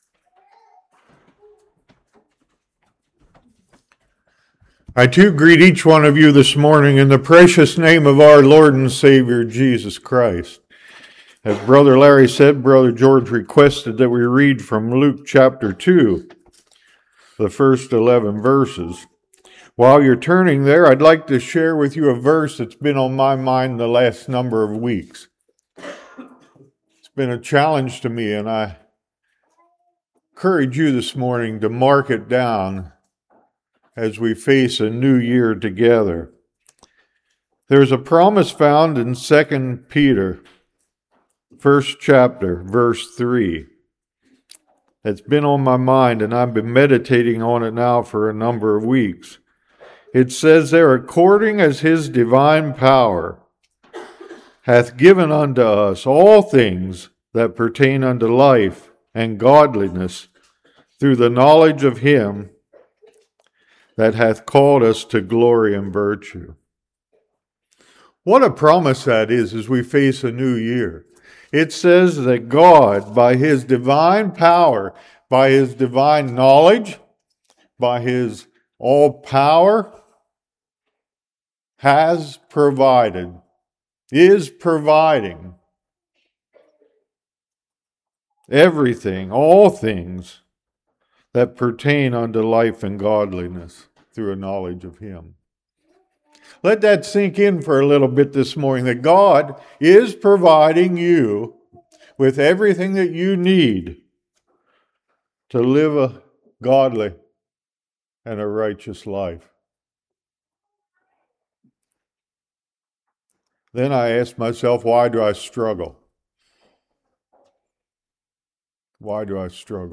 Luke 2:1-11 Service Type: Morning We are Commanded to Fear God.